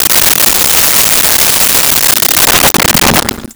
Kitchen Sink
Kitchen Sink.wav